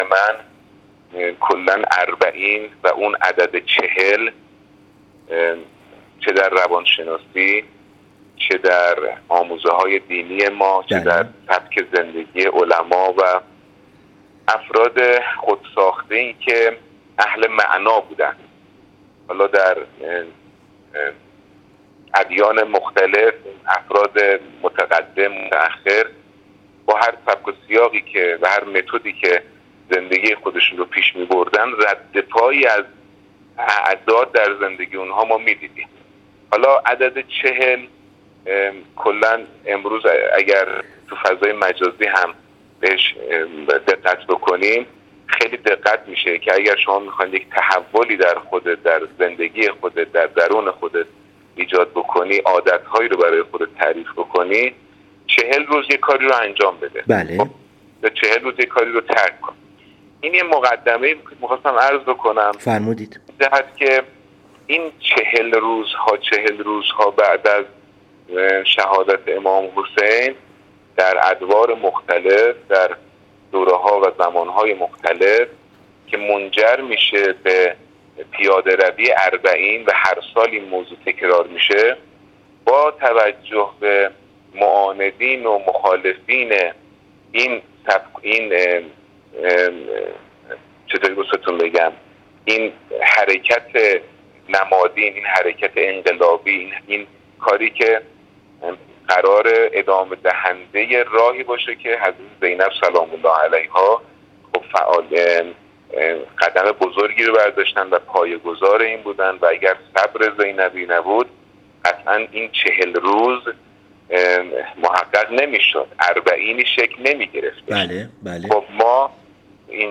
هنرمند عکاس در گفت‌وگو با ایکنا: